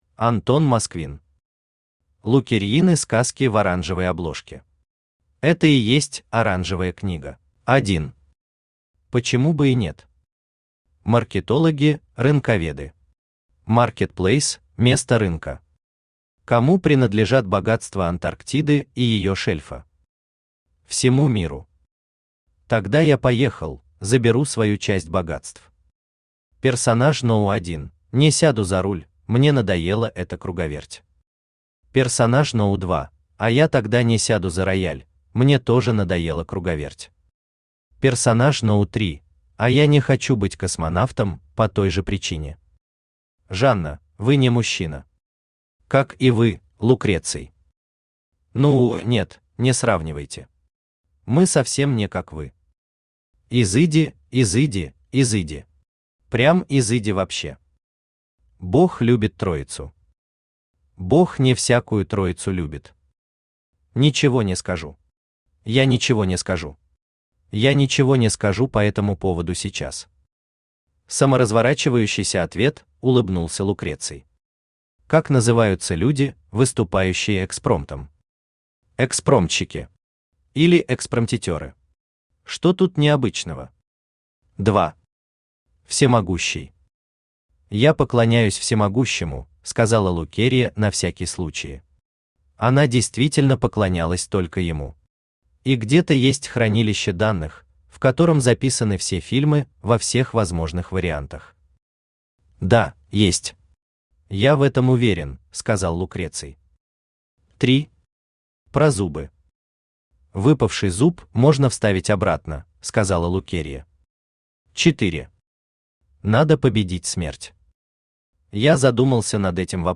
Это и есть оранжевая книга Автор Антон Сергеевич Москвин Читает аудиокнигу Авточтец ЛитРес.